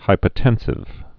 (hīpə-tĕnsĭv)